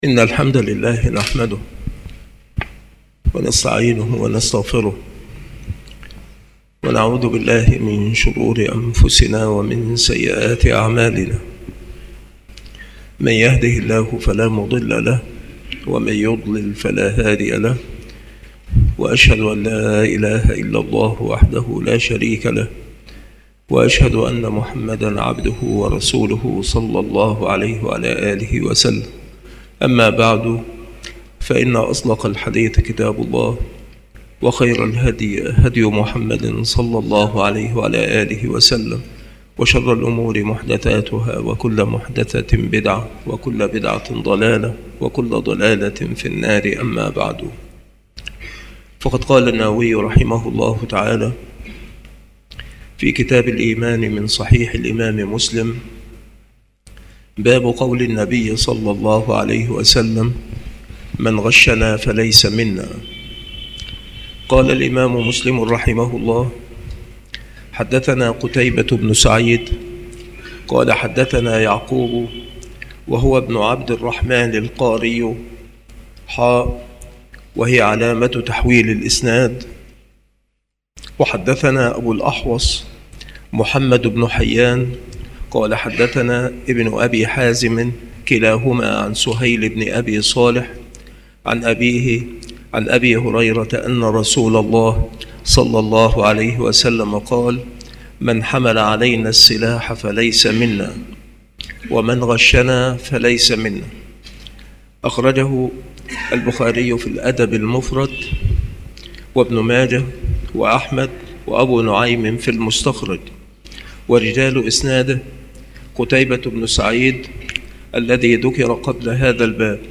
التصنيف شروح الحديث
مكان إلقاء هذه المحاضرة بالمسجد الشرقي بسبك الأحد - أشمون - محافظة المنوفية - مصر